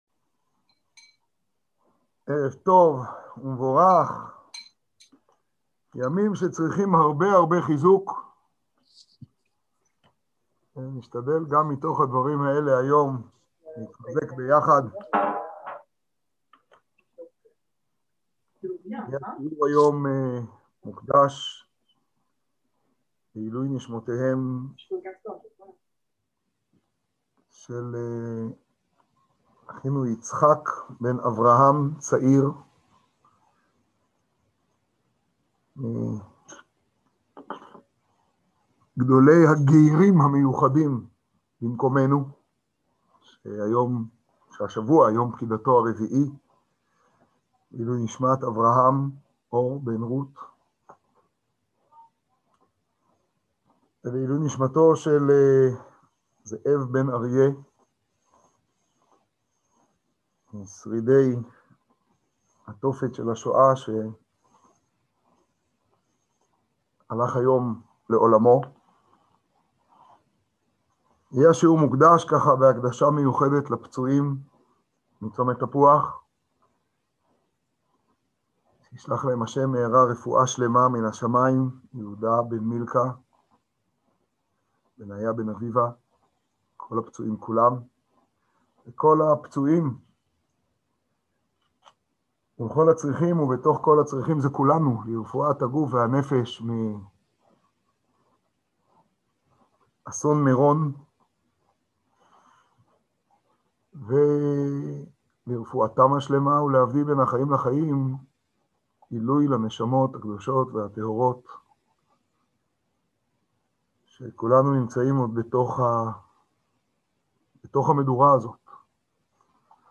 שיעור לפרשת בהר בחוקותיי. תשפא
שיעור לפרשת בהר בחוקותיי.